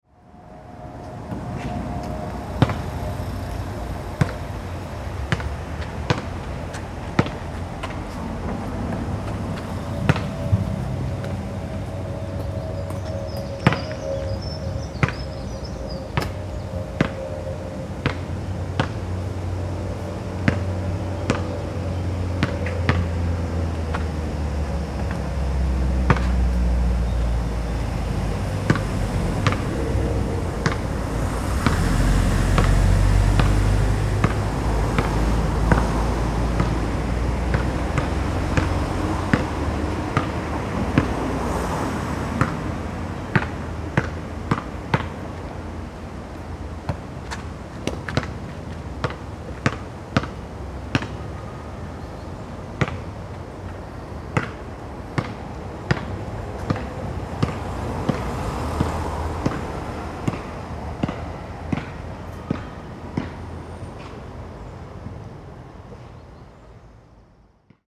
Basket solitaire
87_basket_solitaire.mp3